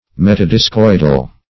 Search Result for " metadiscoidal" : The Collaborative International Dictionary of English v.0.48: Metadiscoidal \Met`a*dis*coid"al\, a. [Meta- + discoidal.]